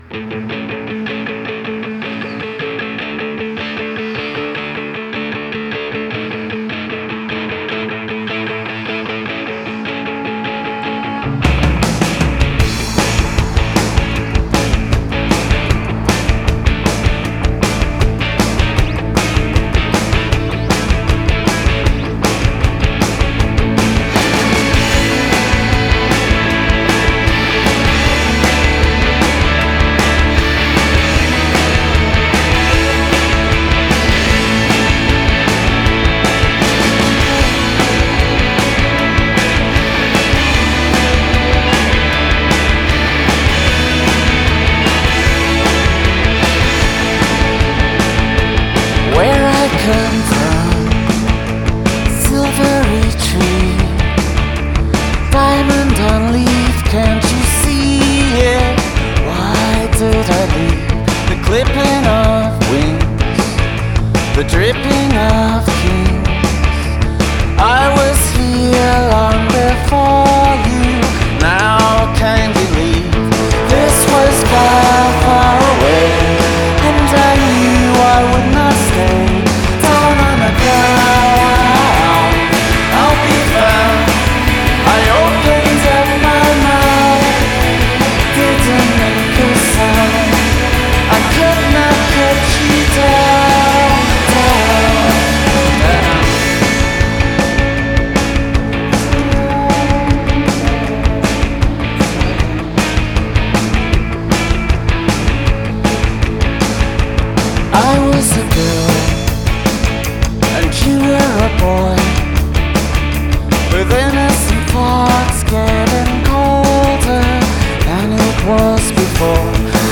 an infectious, rollicking jam
This time out, theirs is a festival sound.